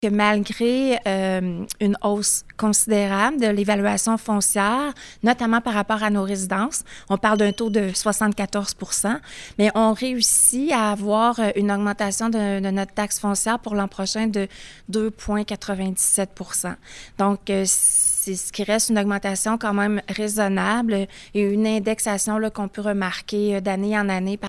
À plusieurs reprises, lors de son énoncé sur le budget 2025, qui était présenté lundi aux médias, la mairesse a évoqué la question des changements climatiques.